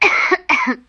cough2.wav